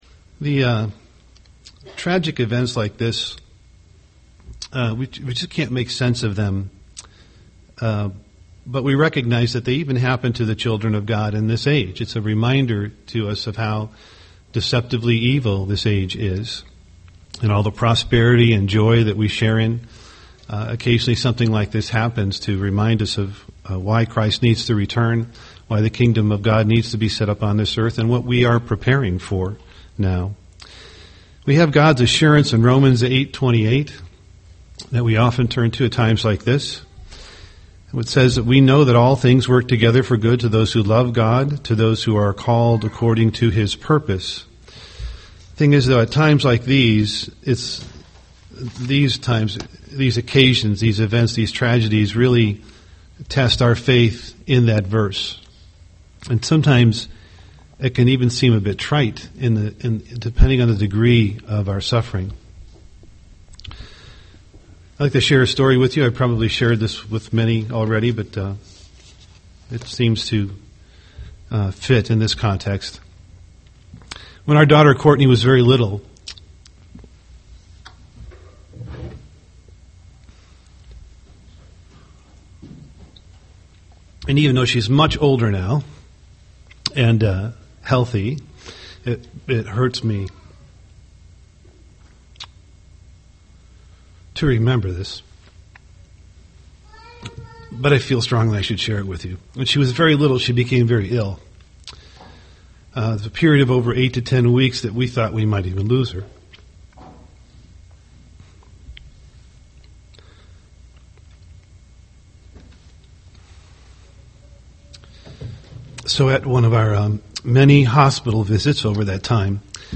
UCG Sermon Trust in God God’s Love Studying the bible?